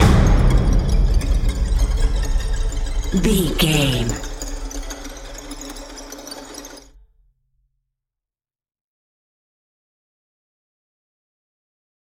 Sound Effects
Atonal
scary
tension
ominous
dark
suspense
dramatic
haunting
eerie
drums
percussion
strings
horror